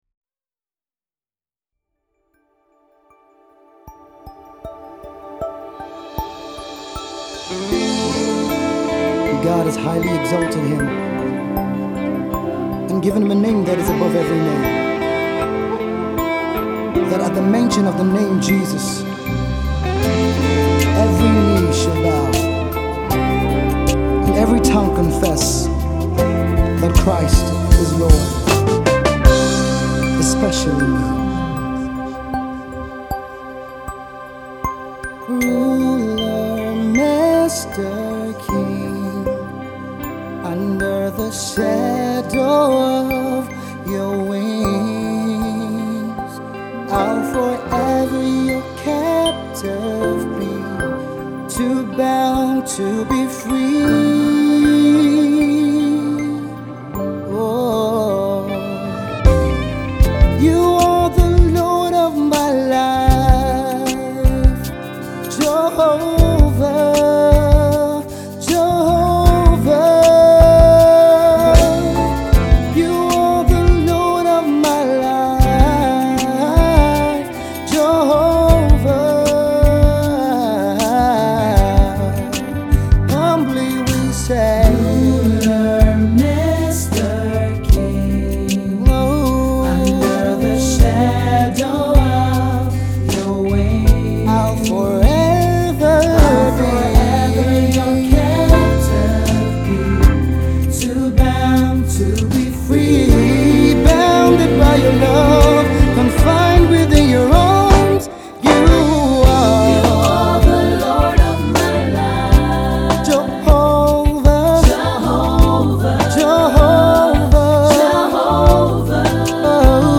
a perfect blend of Gospel, rock and soul
a Rock